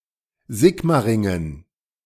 Sigmaringa[cita requerida] (en alemán: Sigmaringen, pronunciación:
/ˈziːkmaʁɪŋən/) es una ciudad del sur de Alemania localizada en el Estado federado de Baden-Wurtemberg, en la Región Administrativa de Tubinga, en el distrito homónimo.
De-Sigmaringen.ogg.mp3